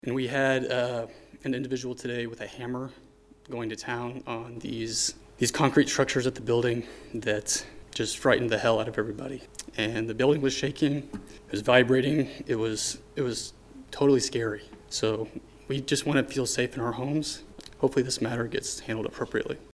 Another resident who spoke to city officials Tuesday